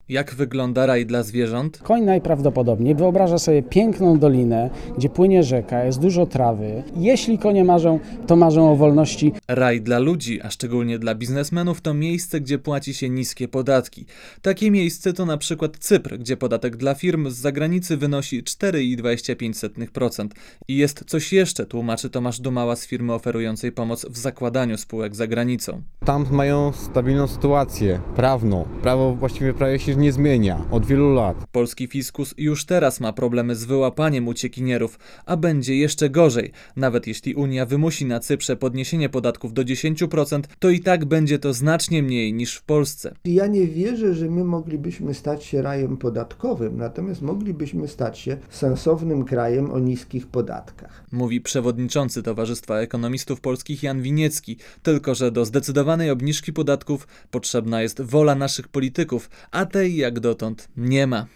U nas płacą 28 procent podatku, a na Cyprze, Gibraltarze czy Węgrzech od zera do ponad 4 procent. 09.12.2002 | aktual.: 09.12.2002 18:03 ZAPISZ UDOSTĘPNIJ SKOMENTUJ © (RadioZet) Posłuchaj relacji